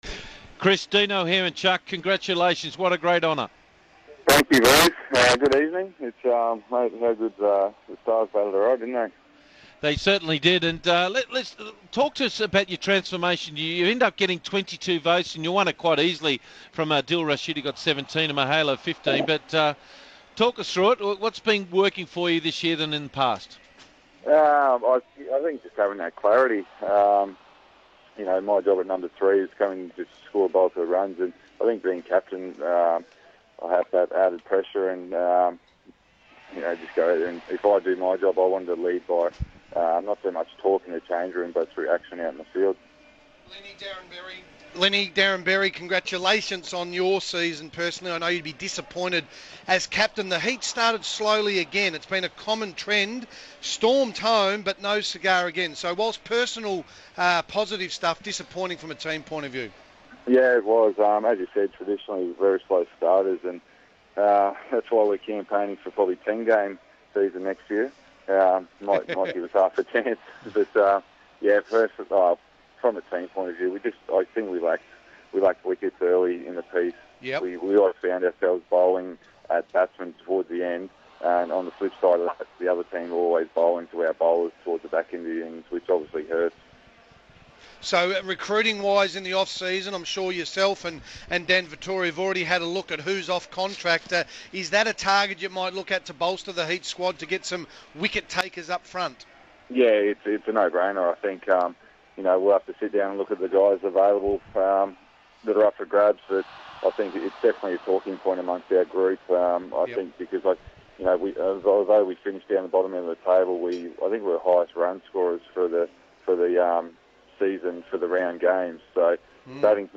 INTERVIEW: Brisbane Heat captain Chris Lynn talks to our commentary team after being named Player of the BBL05.